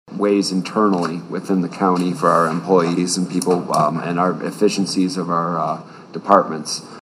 Vice chair John Taylor says they should keep the money in house to enhance county operations.